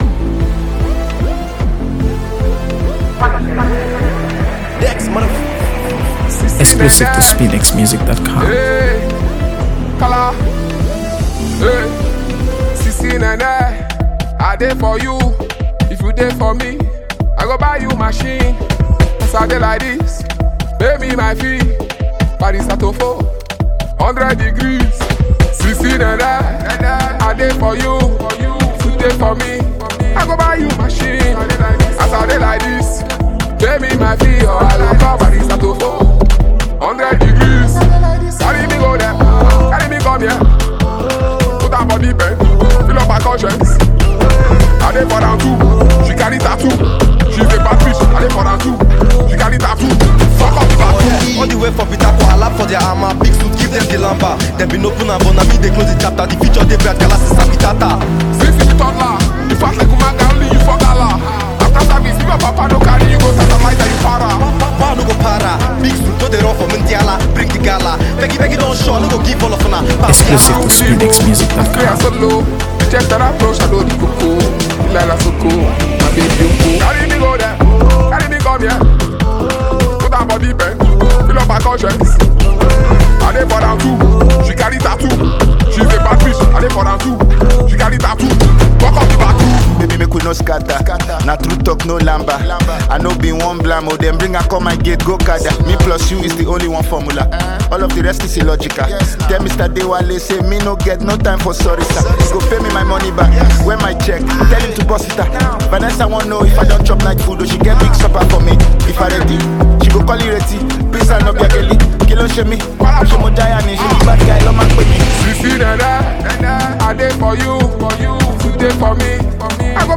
AfroBeats | AfroBeats songs
Nigerian rapper, performer
a powerful blend of sharp lyricism and magnetic energy
the song features layered production